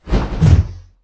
swing2.wav